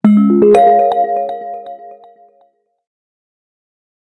notification_017.ogg